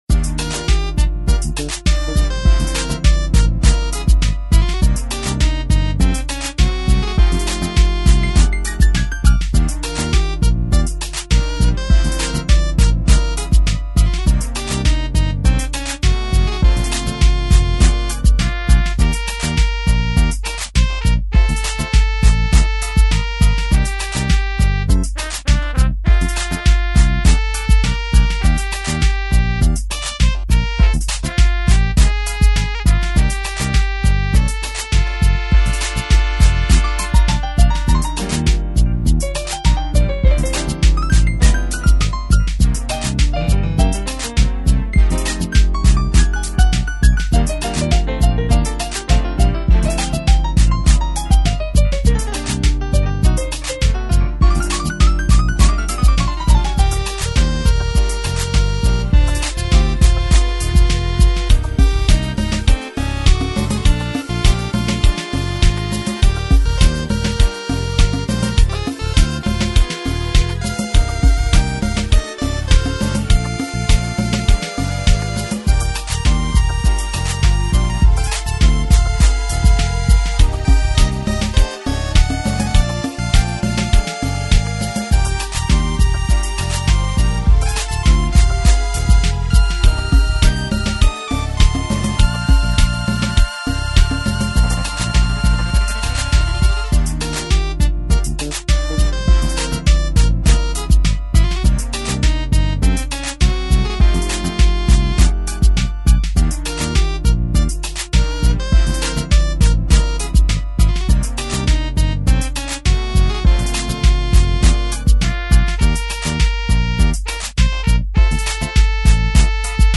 ＱＹ１０のサウンド
これが常にＱＹ１０を野外に持ち出して作っていた時の曲です。